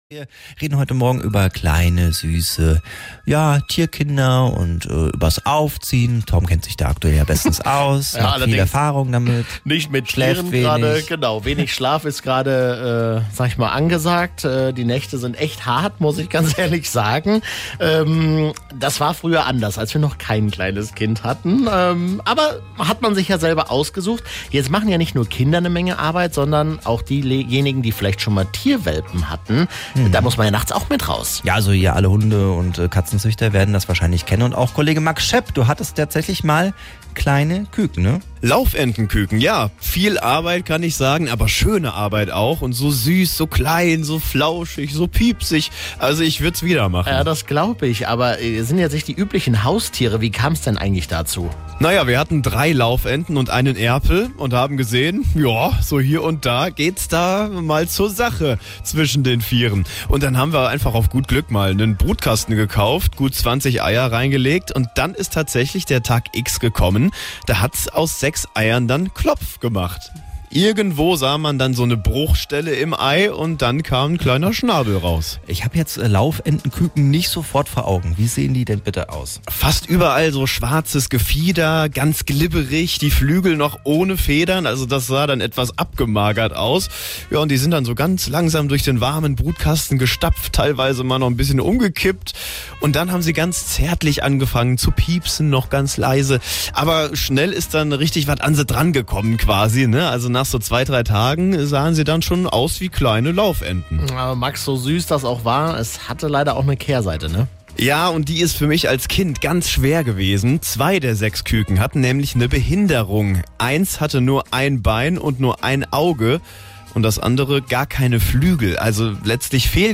Laufentenküken